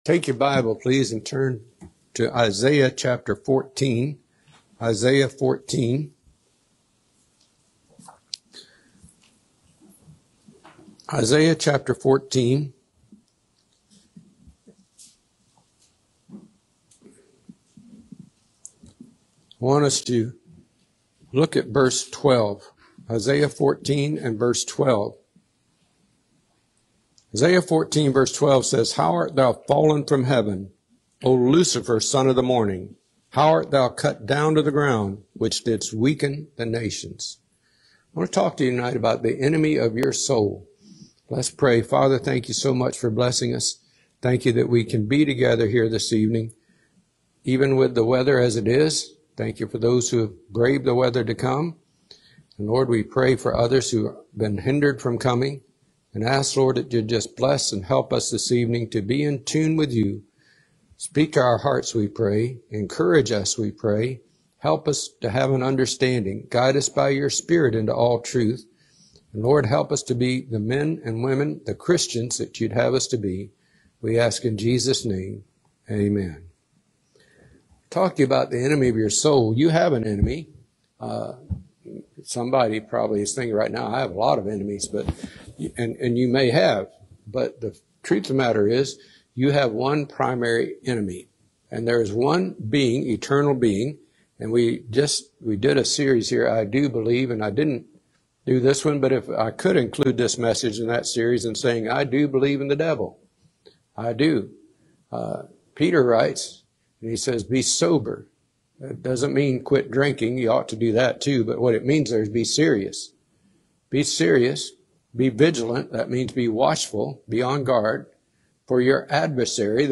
Uncover the tactics of The Enemy of Your Soul in this sermon. Explore Isaiah 14:12-17 to learn how to stand firm.